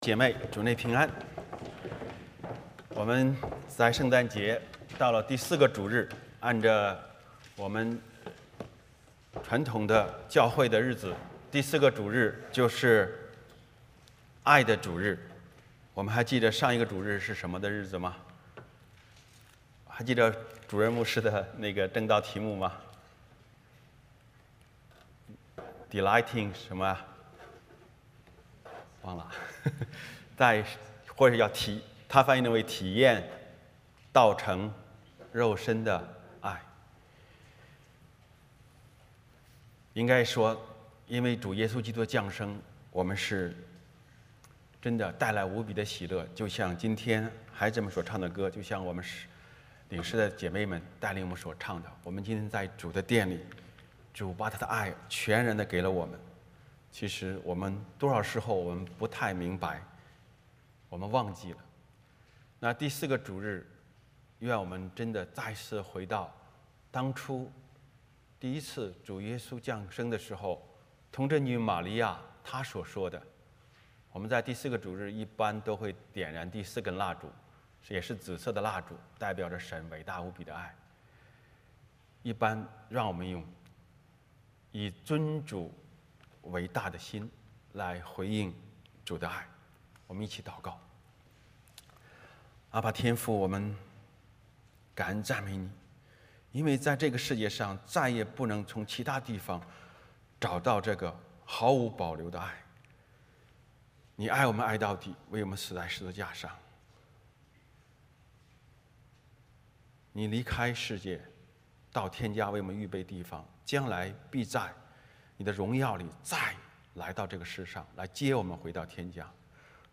召 以赛亚书 9:2, 9:6 诗歌 / 祷告 我在这里敬拜 如此认识我 奉献 在祢殿中 儿童献诗 读经 约翰一书 4:7-21 证道 这就是爱了 回应诗 是为了爱 三一颂 领受祝福 欢迎/家事分享